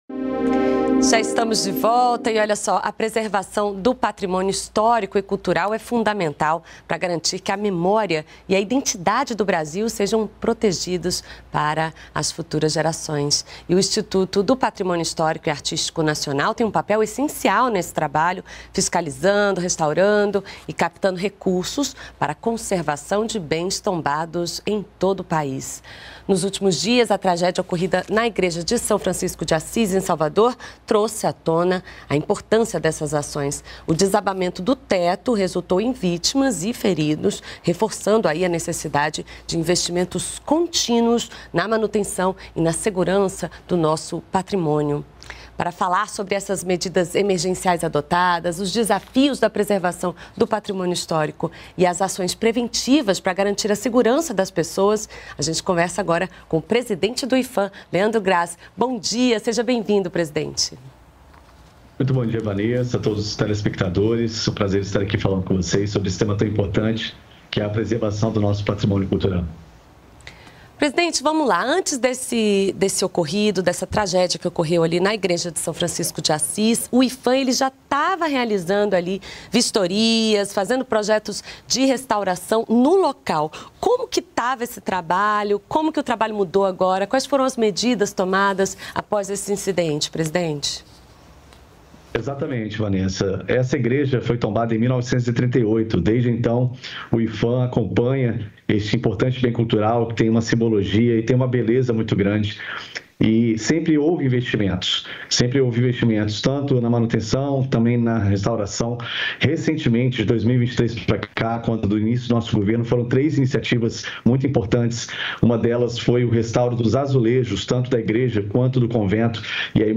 Para detalhar as medidas emergenciais adotadas, os desafios da preservação do patrimônio histórico e as ações preventivas para garantir a segurança das pessoas, o Brasil em Dia conversa com o presidente do Iphan, Leandro Grass.
Brasil em Dia - Entrevista